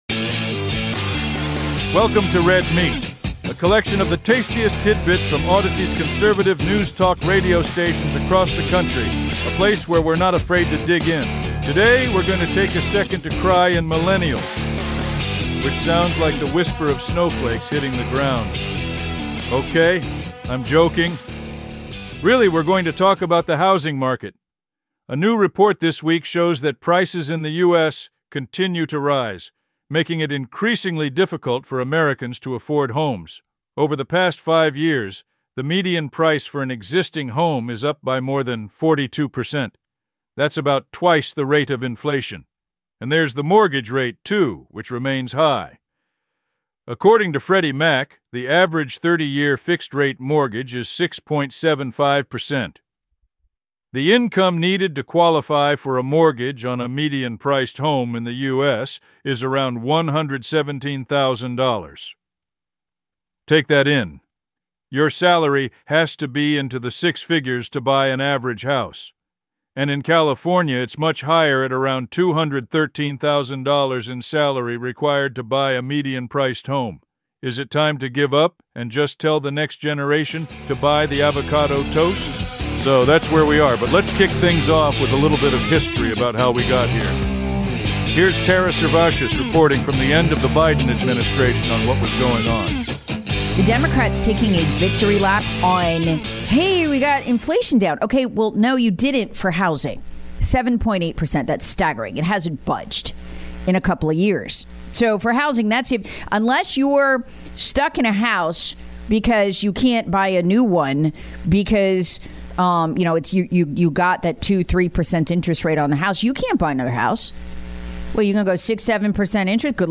Play Rate Listened List Bookmark Get this podcast via API From The Podcast Politics, crime, law and order and more are rounded up here in this assortment of the tastiest bites of audio from Audacy talk stations across the United States.